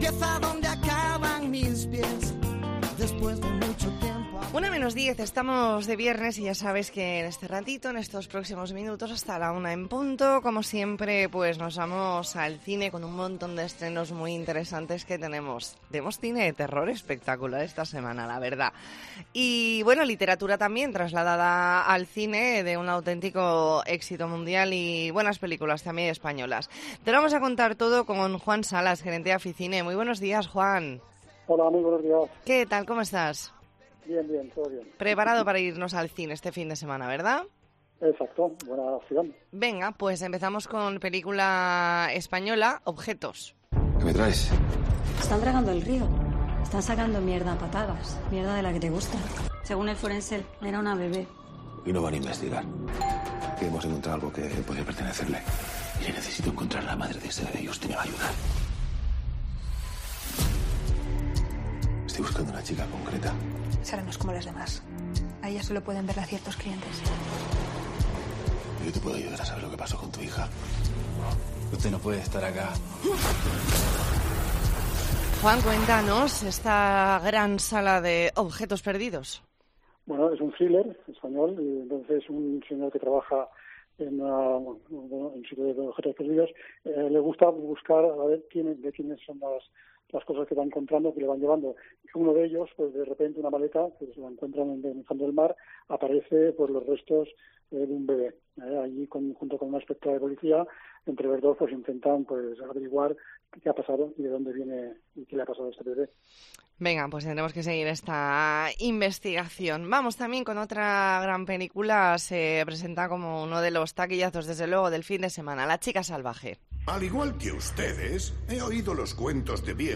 Entrevista en La Mañana en COPE Más Mallorca.